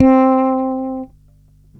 38-C4.wav